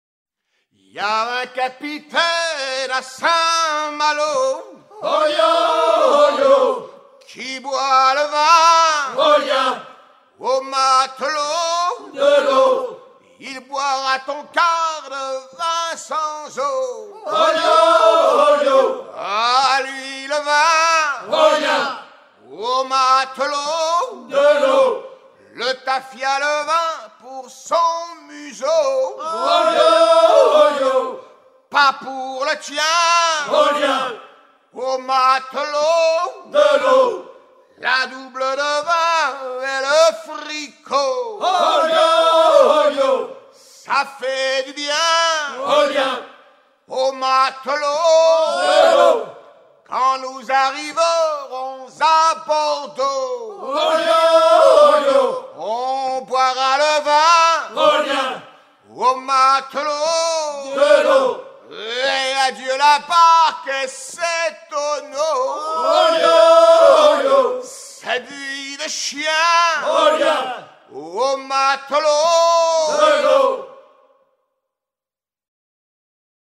gestuel : à haler
circonstance : maritimes